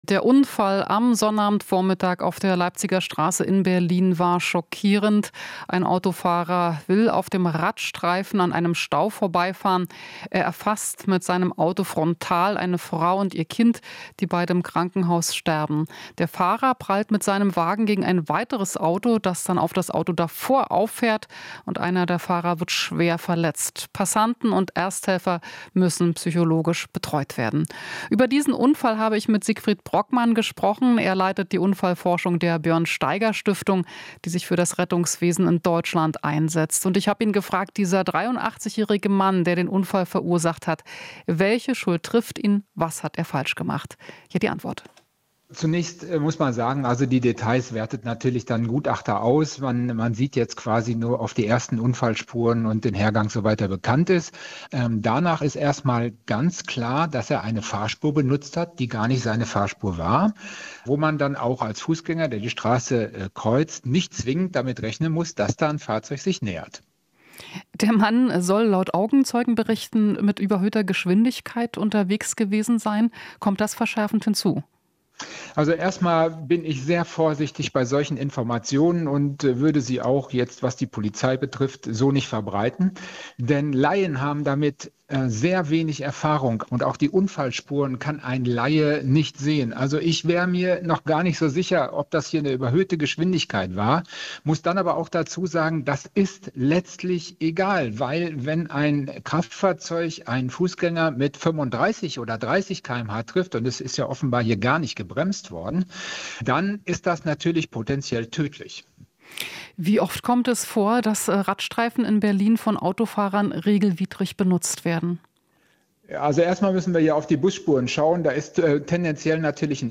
Interview - Unfallforscher kritisiert Markierungssituation